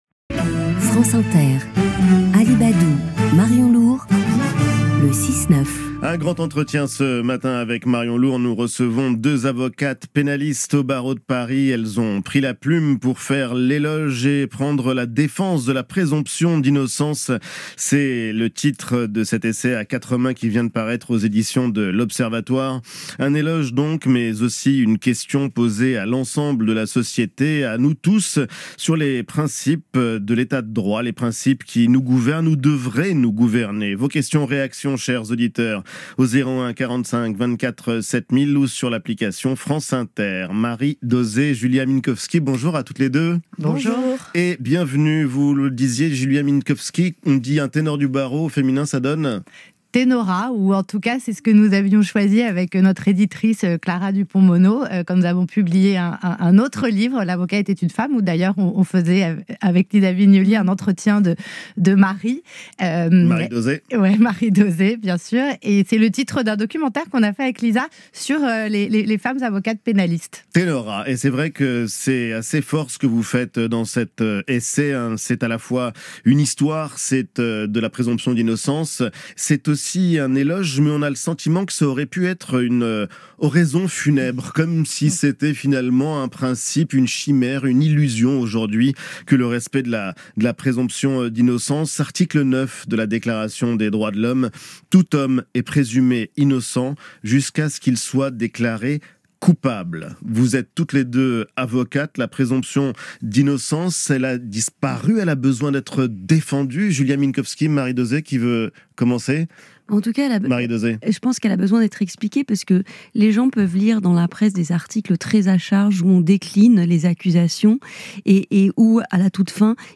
Diffusé sur France Inter le 8 février 2025
Radio